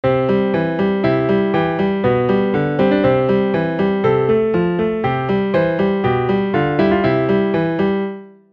今日の症状に合わせてメニエール体験音源を作った（暇人かよ）。
元音源を再生すると